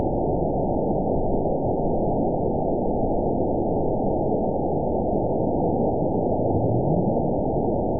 event 920450 date 03/26/24 time 02:12:16 GMT (1 year, 1 month ago) score 9.28 location TSS-AB02 detected by nrw target species NRW annotations +NRW Spectrogram: Frequency (kHz) vs. Time (s) audio not available .wav